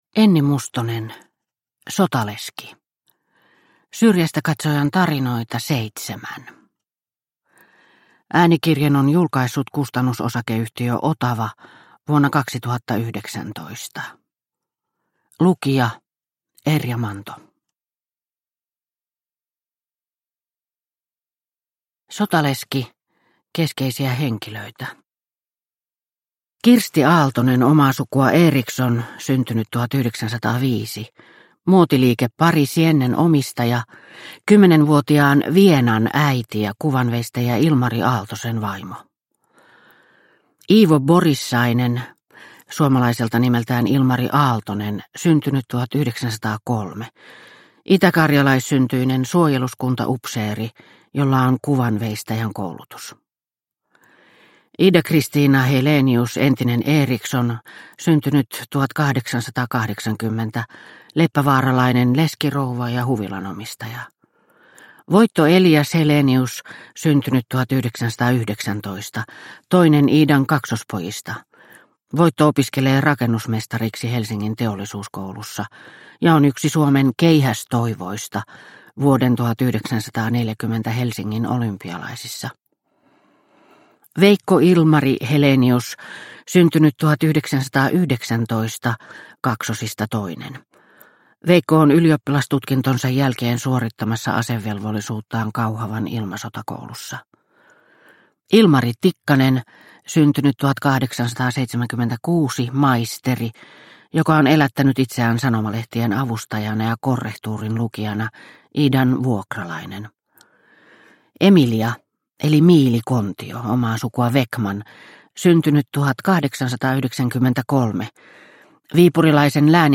Sotaleski – Ljudbok – Laddas ner